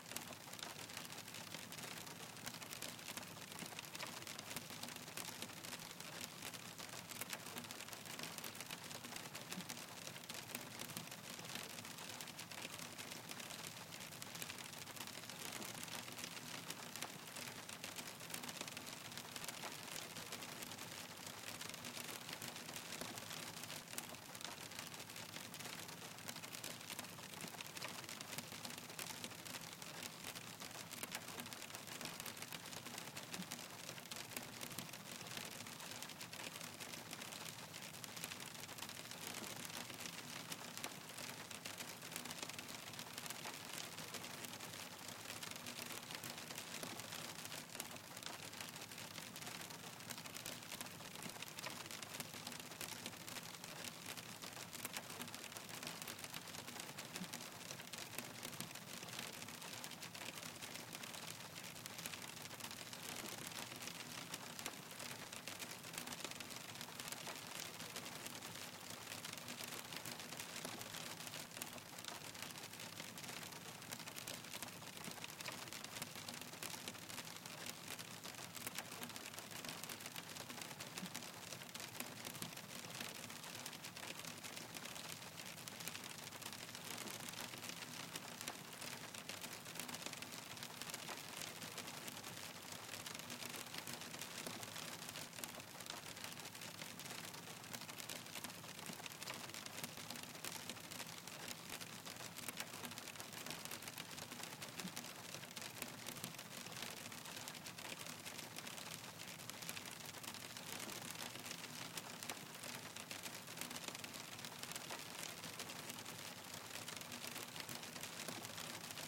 Звуки града
Шум природы: падает град